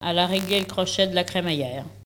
Localisation Sallertaine
Locutions vernaculaires